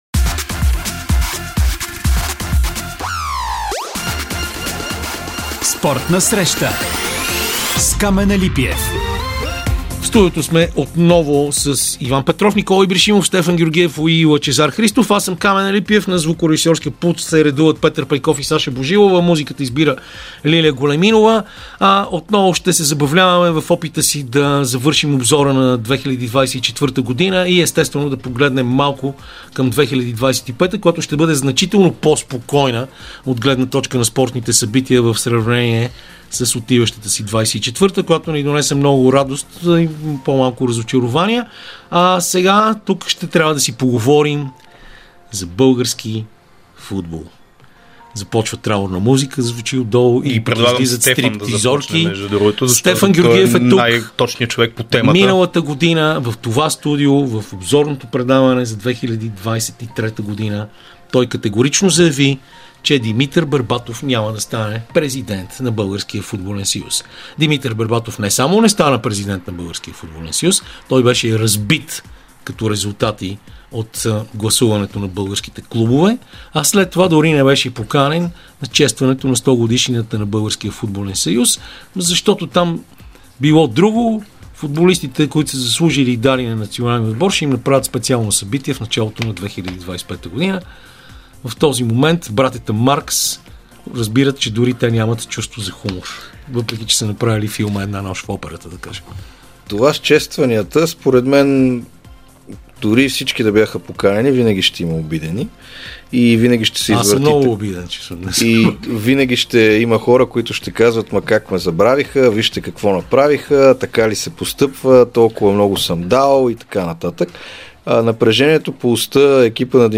Слушайте предаването всяка неделя от 16 часа по Радио София